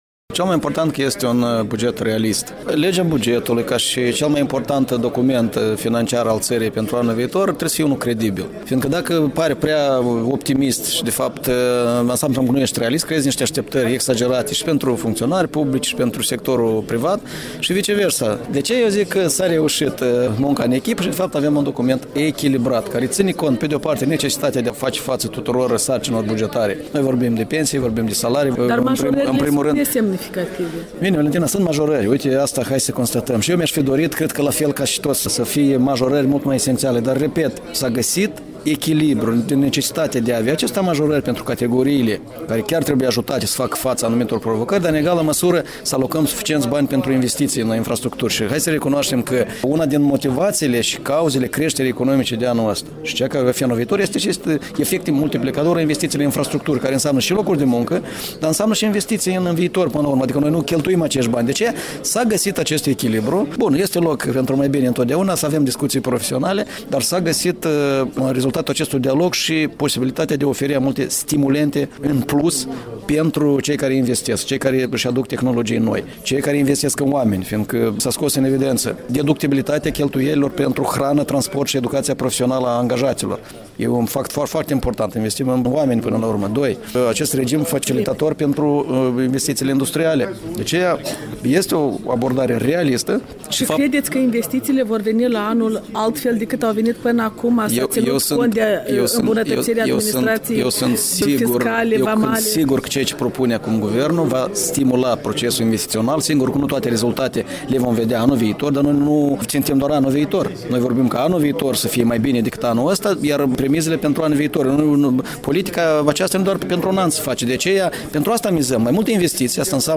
Interviu cu vicepremierul şi ministrul economiei Valeriu Lazăr